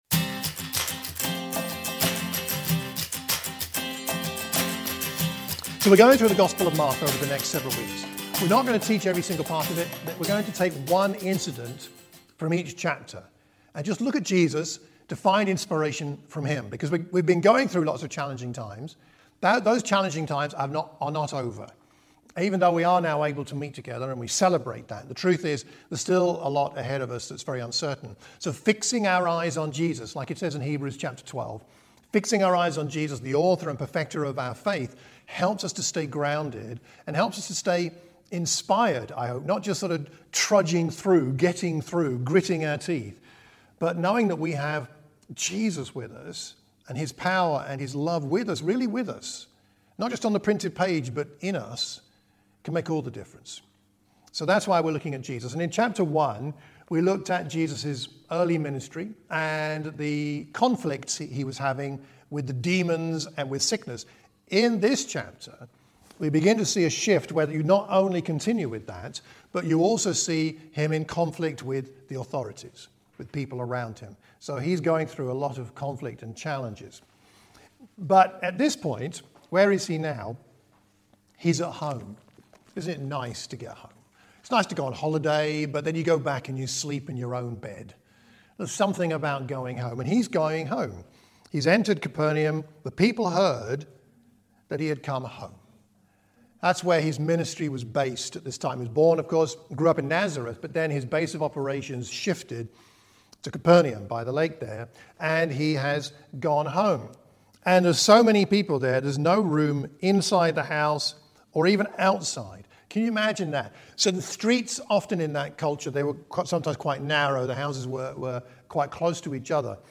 A sermon for the Watford Church of Christ.